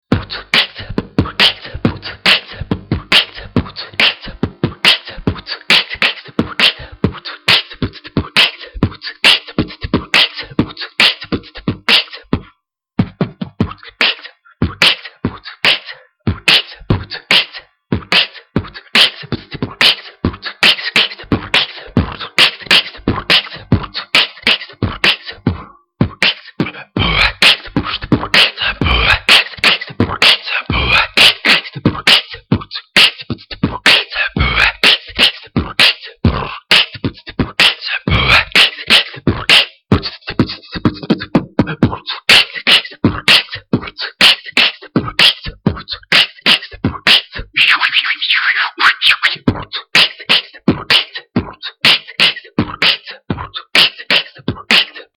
Биты хорошие у тебя) скорость в норме) четкость есть)
только вот однообразно к концу записи пошло мне кажется...
Единственное вот только - однообразно все...